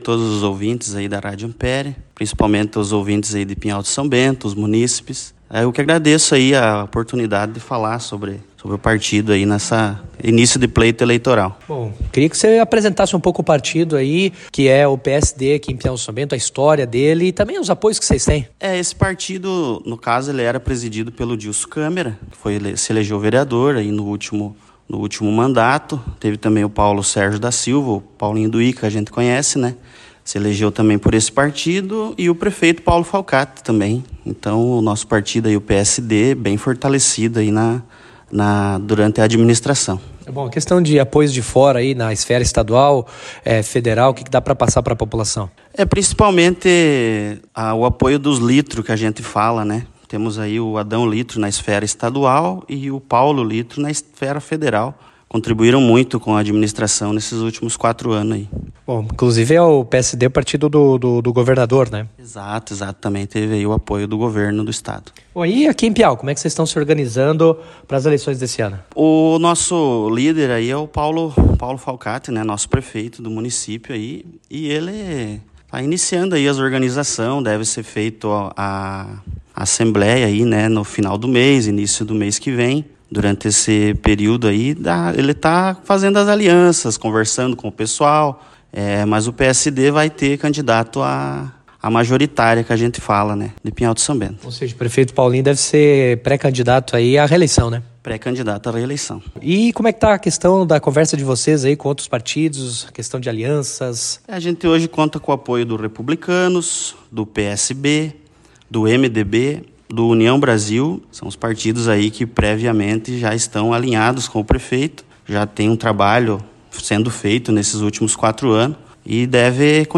A série de entrevistas continua até a próxima quinta-feira, 18. Cada partido tem até cinco minutos para apresentar sua organização para as convenções e o pleito eleitoral de outubro.